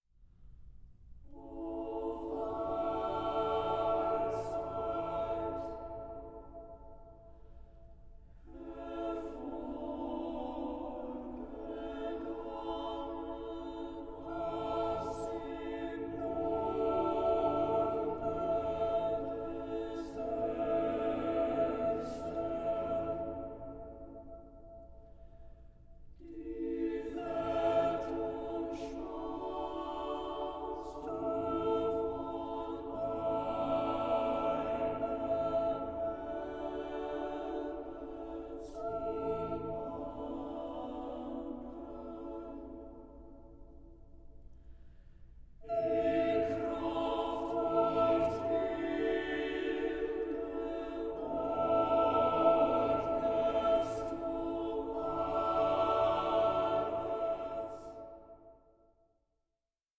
contemporary Scandinavian and Baltic choral music